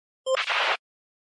f1 radio end Meme Sound Effect
f1 radio end.mp3